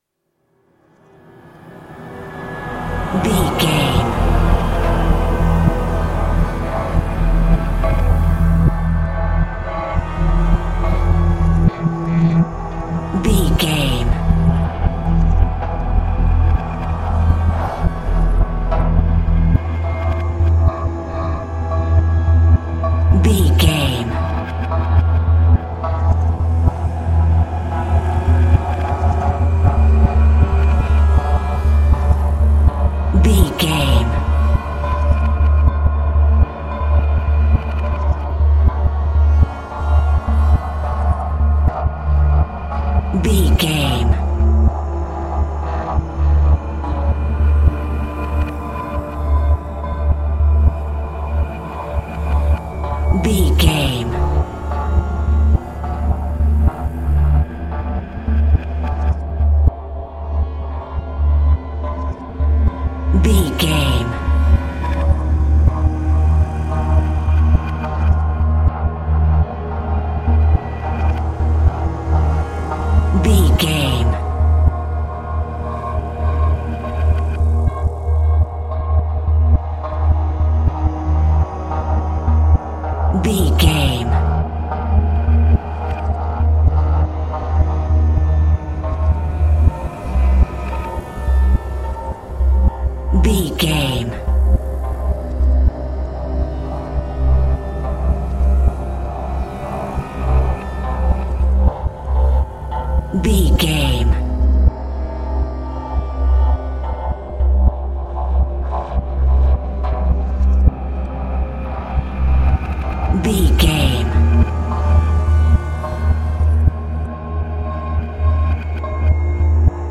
Thriller
Aeolian/Minor
E♭
Slow
synthesiser
tension
ominous
dark
suspense
haunting
creepy